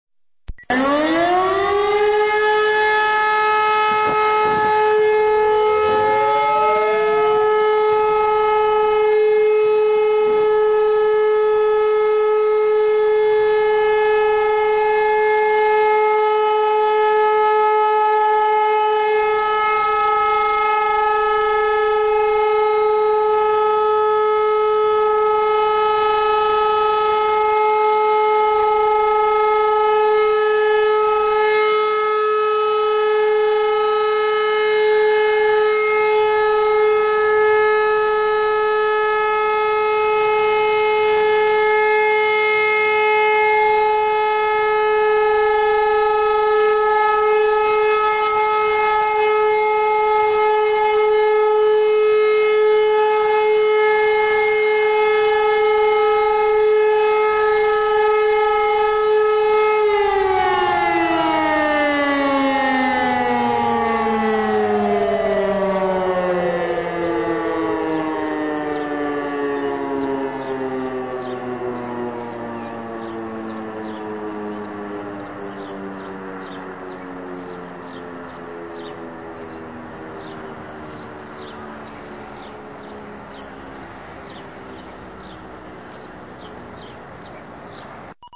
Hört selbst, wie am 10. September um 11.00Uhr bzw. 11.20Uhr die Sirenen in Deutschland heulen werden.
Entwarnung.mp3